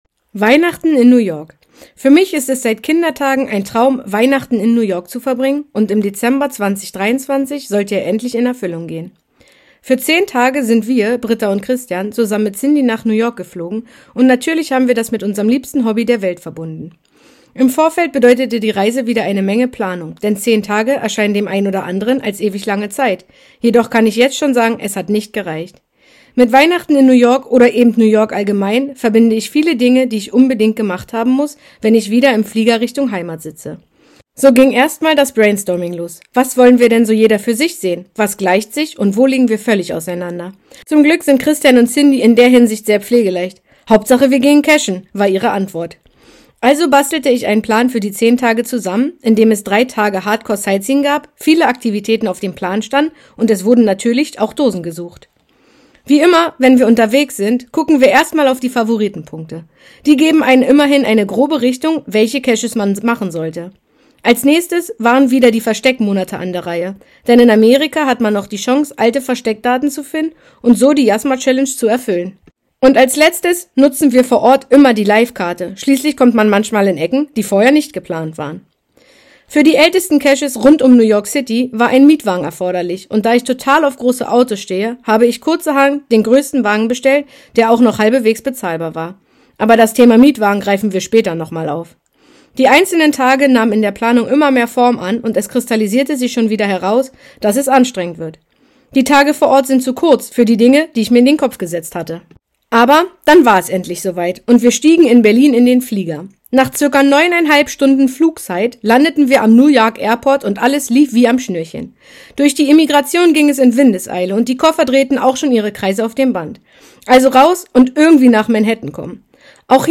Weihnachten und Geocaching in New York - Hörfassung - Geocaching Magazin - von Cachern für Cacher